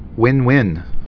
(wĭnwĭn)